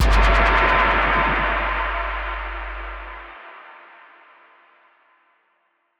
Synth Impact 15.wav